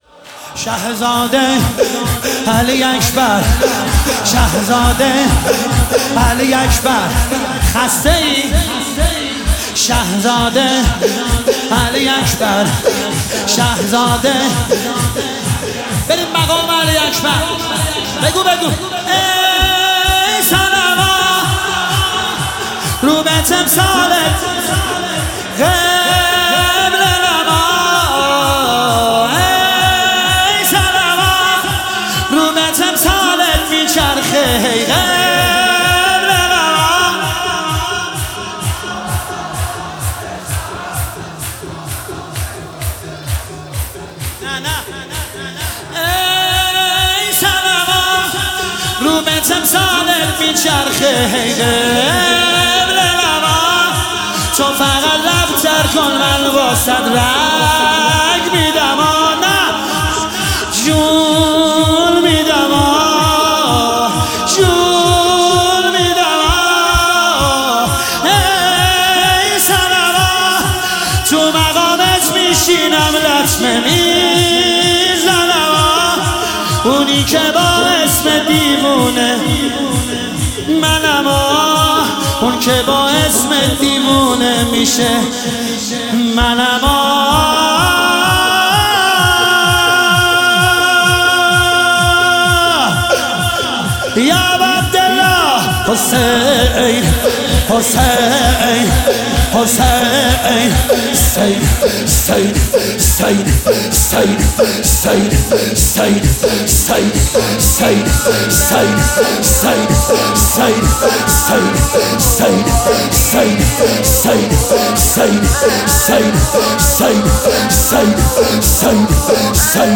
شب بیست و یکم ماه رمضان شب قدر